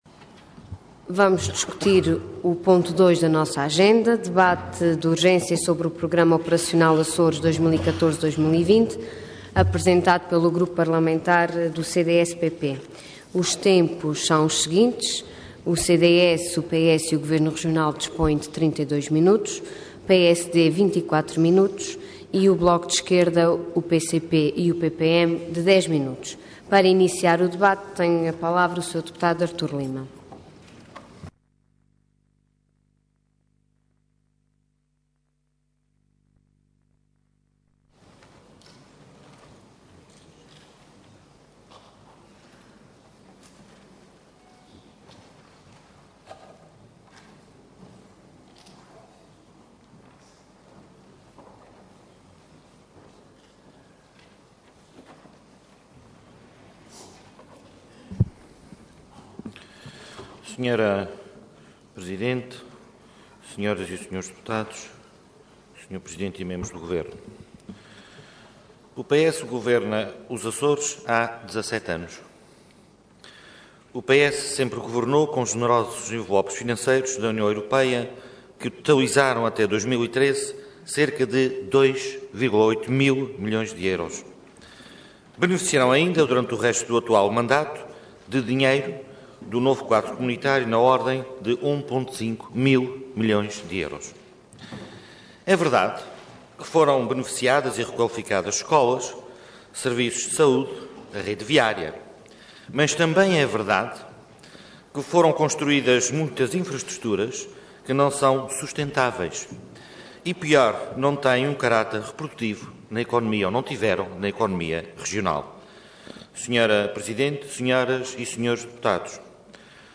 Detalhe de vídeo 7 de maio de 2014 Download áudio Download vídeo Processo X Legislatura Programa Operacional Açores 2014-2020 Intervenção Debate de urgência Orador Artur Lima Cargo Deputado Entidade CDS-PP